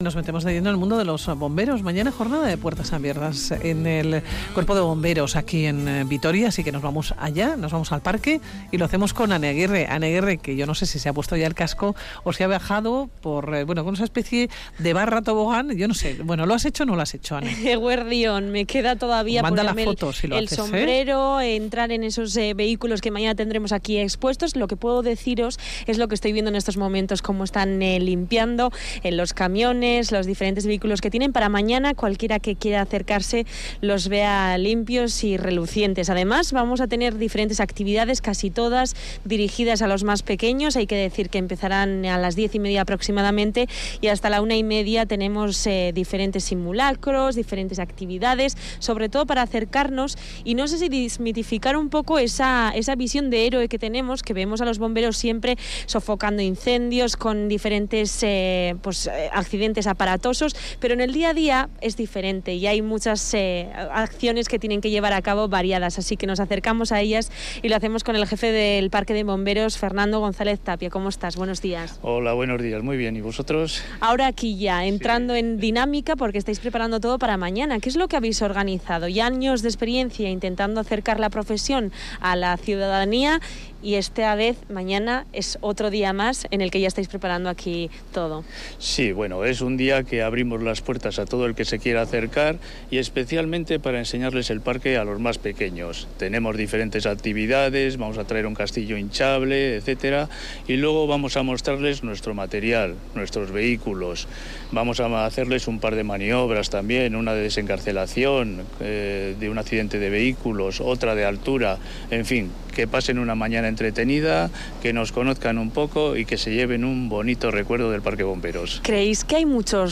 Radio Vitoria| Hoy nos acercamos con la unidad móvil al parque de bombreros. A la profesión, al día a día y a su historia.